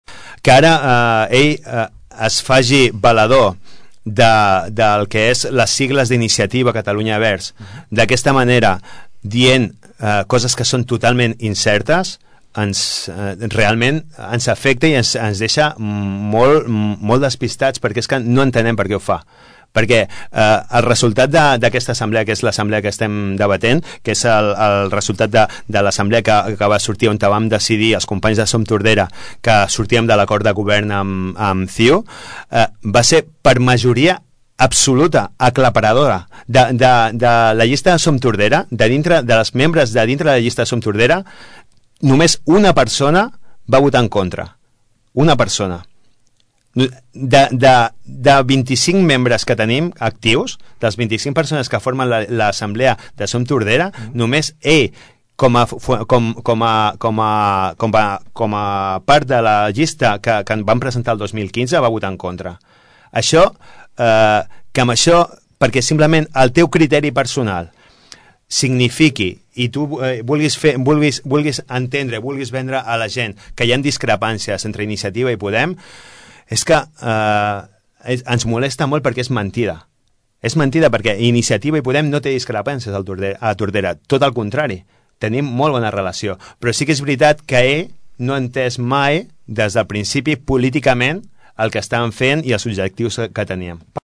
Així ho explicava aquesta tarda, el regidor i portaveu de Som Tordera, Salvador Giralt en una entrevista a Ràdio Tordera.